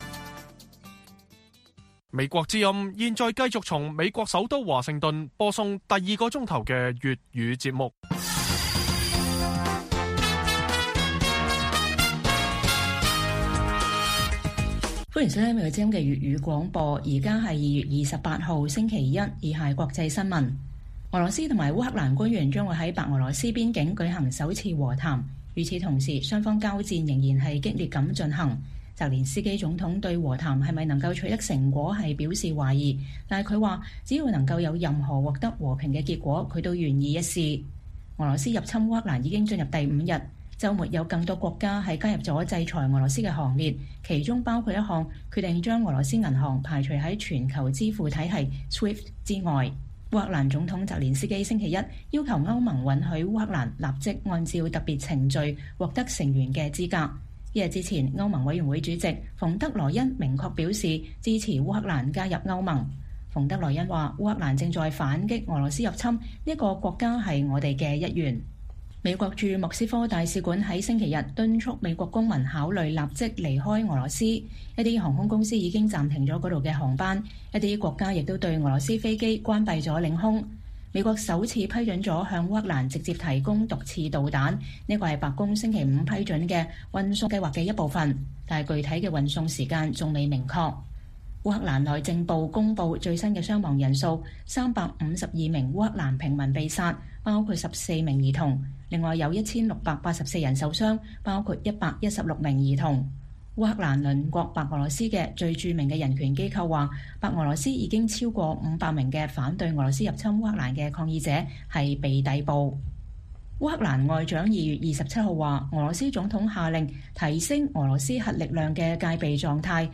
粵語新聞 晚上10-11點: 香港民主派47人被控顛覆罪一年未定審期 政黨批未審先囚剝奪探視